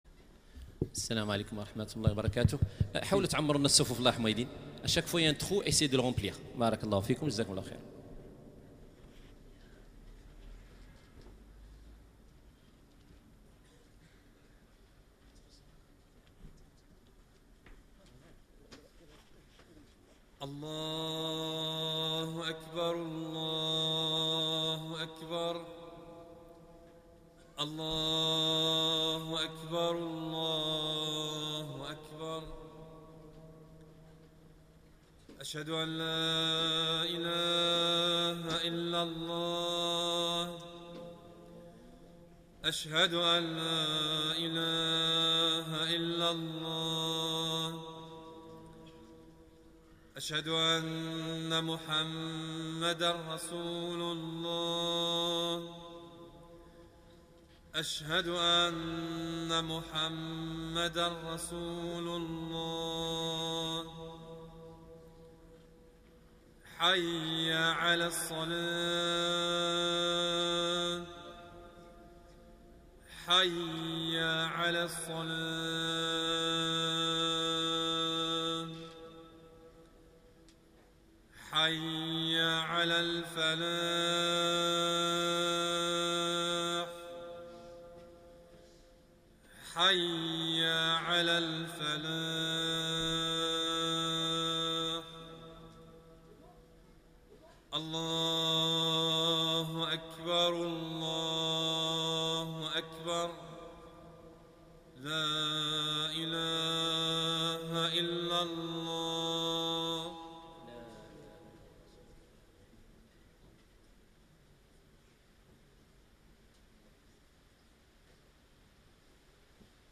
Les prêches du Vendredi